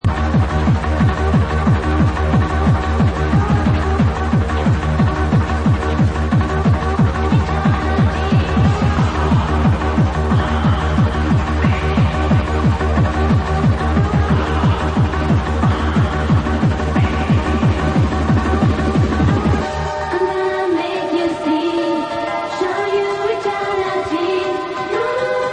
old dancefloor track
i found it on an old tape.